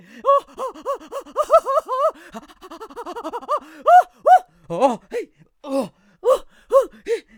traf_screams2.wav